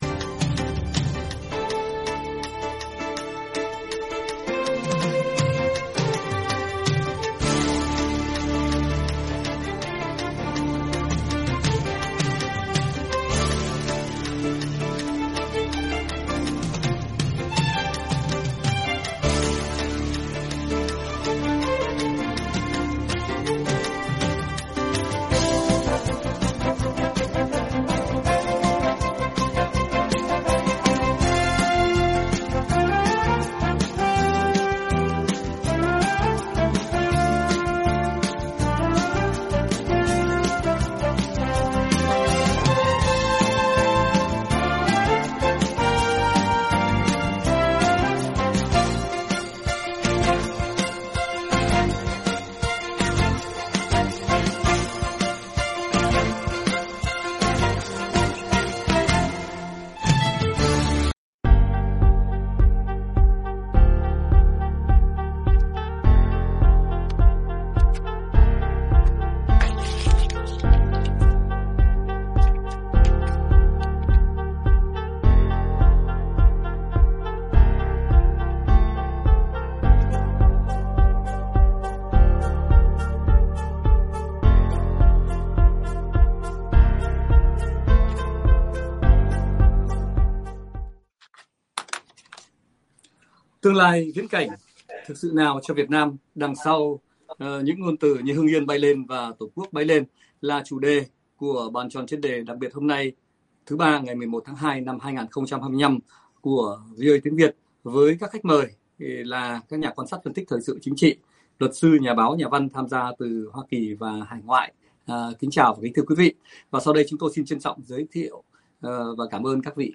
Bàn Tròn Chuyên Đề đặc thứ Ba ngày 11/02/2025 của VOA Tiếng Việt, với các khách mời là các nhà quan sát, phân tích thời sự chính trị, luật sư, nhà báo nhà văn tham gia từ Hoa Kỳ và hải ngoại.